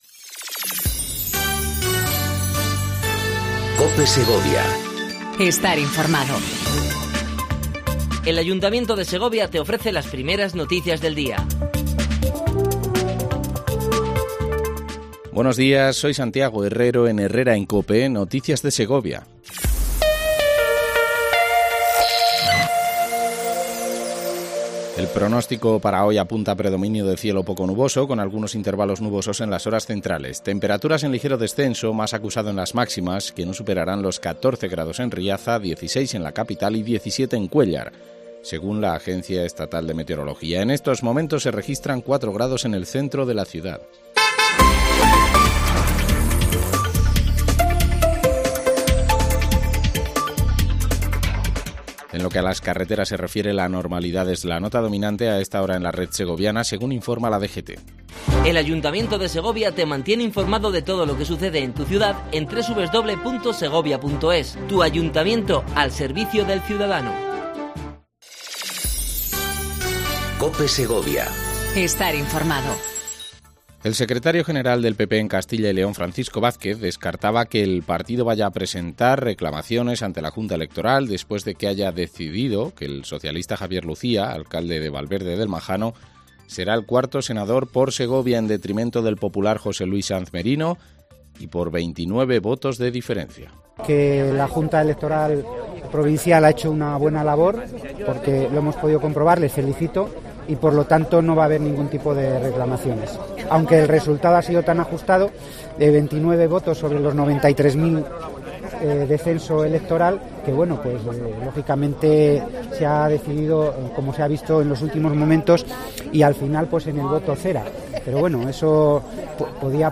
INFORMATIVO 07:55 COPE SEGOVIA 03/05/19
AUDIO: Primer informativo local en cope segovia